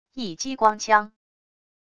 1激光枪wav音频